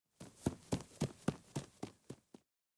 小孩从近到远跑步.ogg